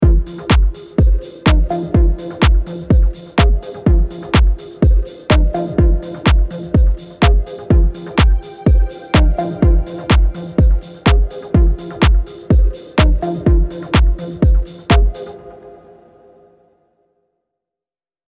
Genre House
BPM 125